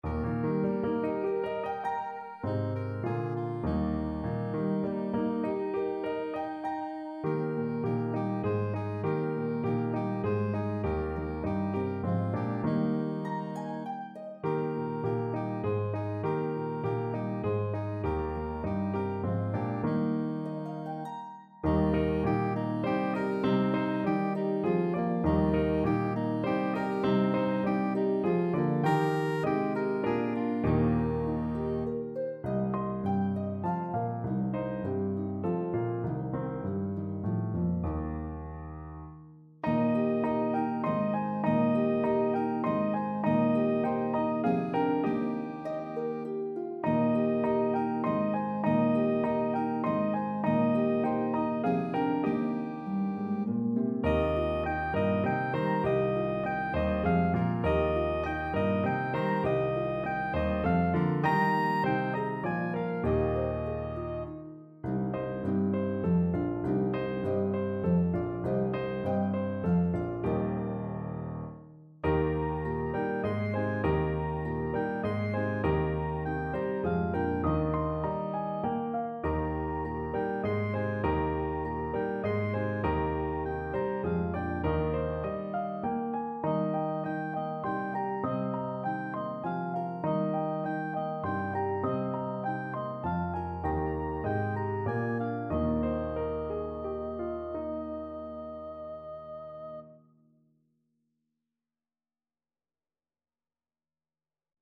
Their trip to the manger is rhythmically interesting!